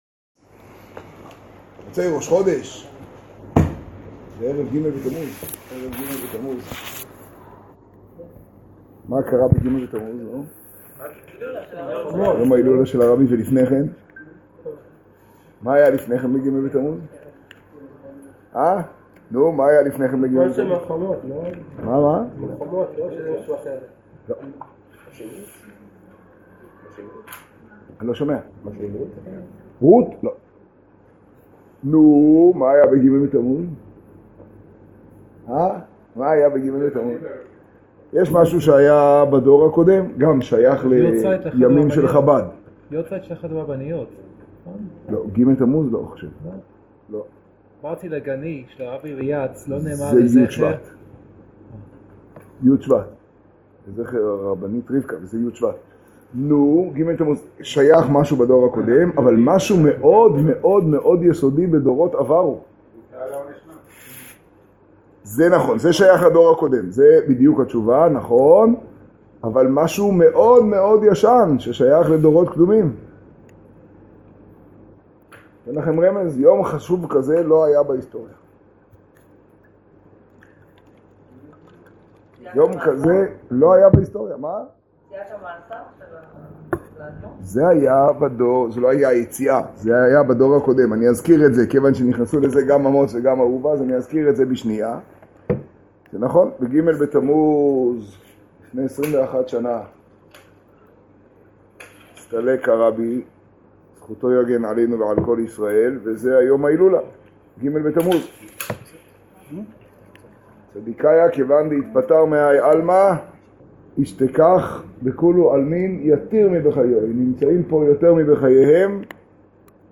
השיעור במגדל, פרשת קורח תשעה.